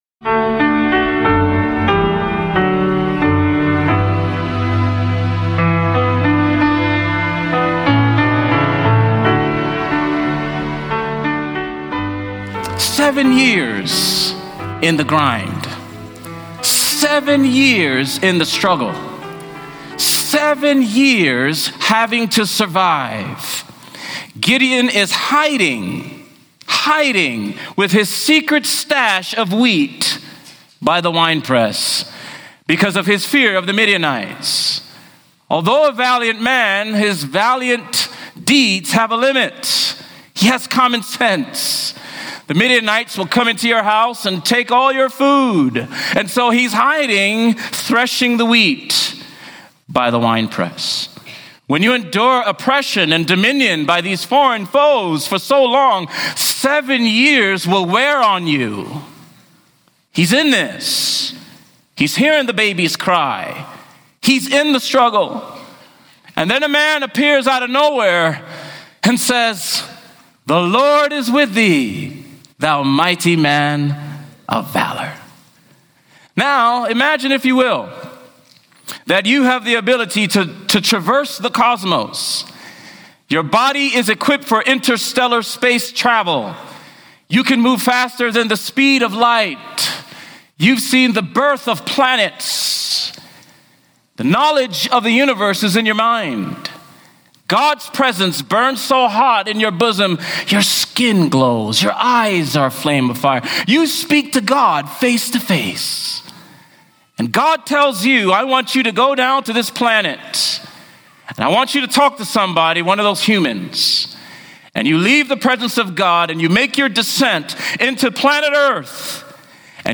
This sermon explores how faith, obedience, and divine encounters can transform fear into courage, reminding us that true strength comes from trusting in God’s plan over our own limitations.